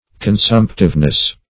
Search Result for " consumptiveness" : The Collaborative International Dictionary of English v.0.48: Consumptiveness \Con*sump"tive*ness\, n. A state of being consumptive, or a tendency to a consumption.
consumptiveness.mp3